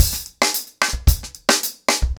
TimeToRun-110BPM.17.wav